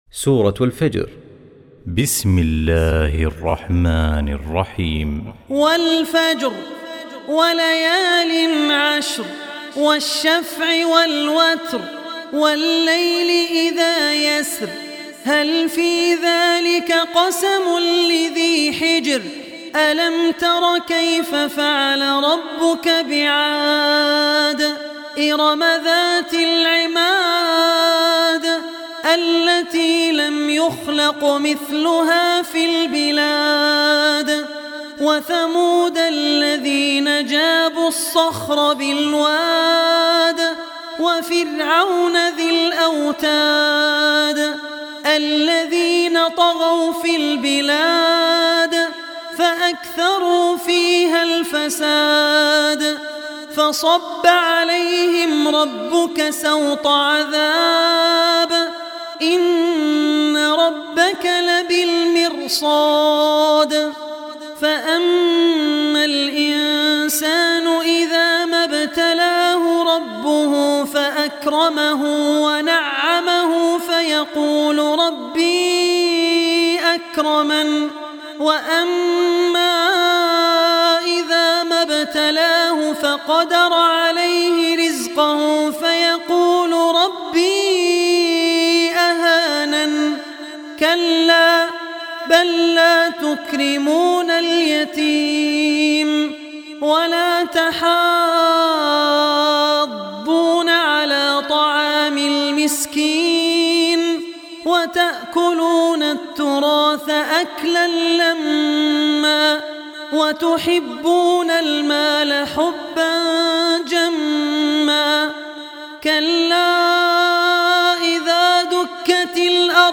Surah Al-Fajr Recitation